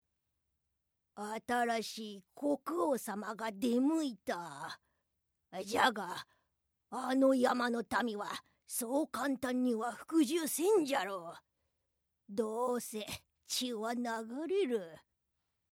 アニメ　老婆